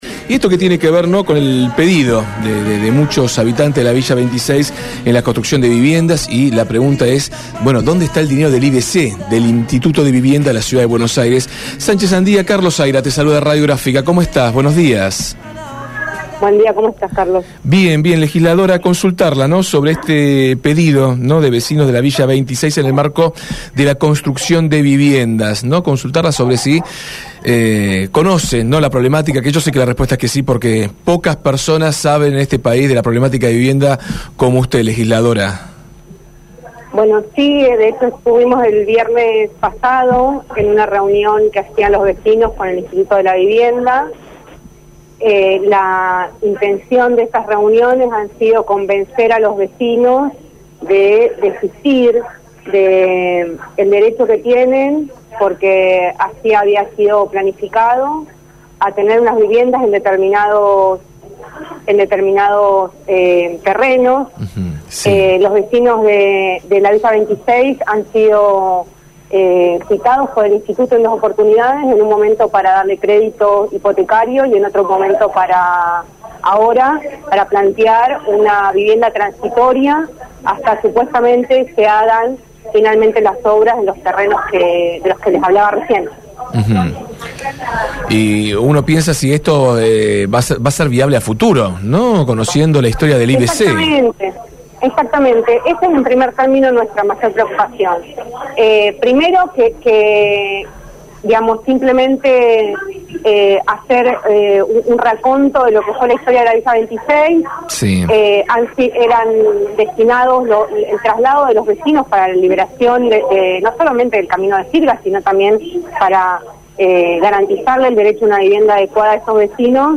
Rocío Sánchez Andía, legisladora porteña, habló en Desde el Barrio.